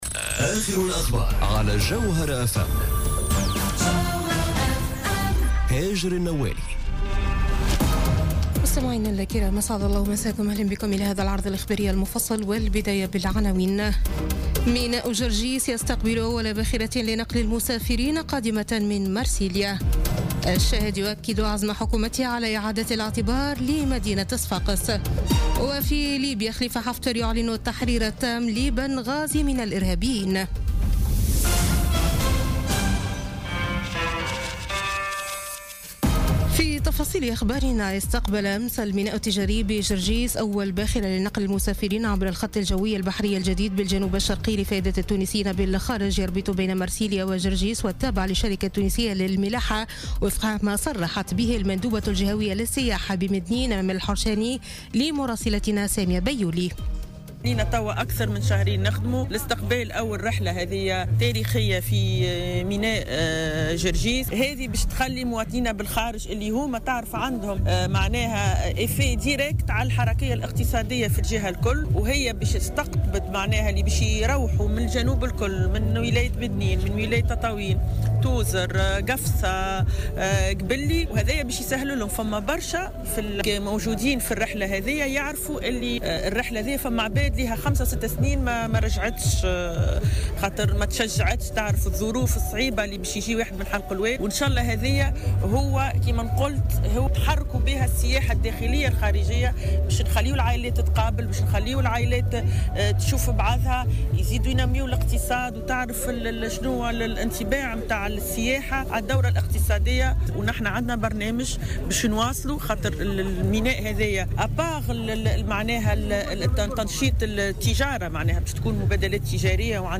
نشرة أخبار منتصف الليل ليوم الخميس 06 جويلية 2017